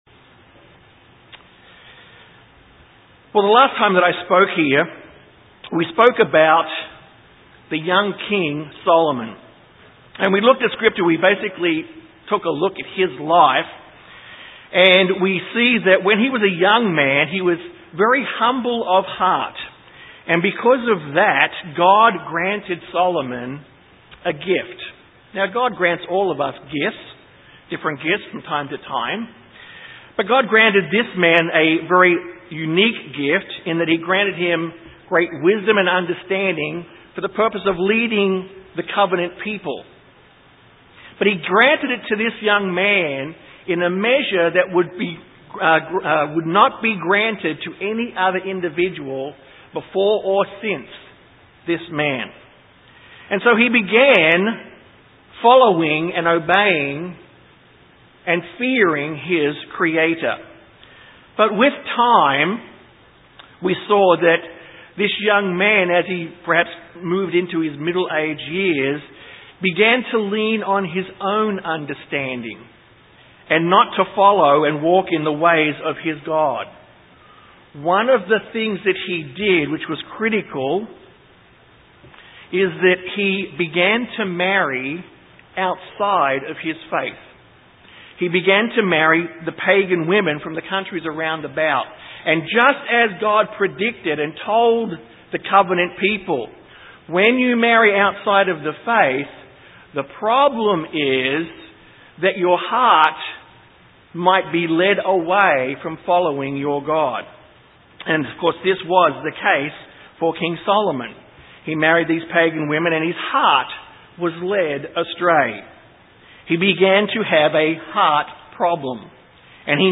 Given in San Antonio, TX
UCG Sermon Studying the bible?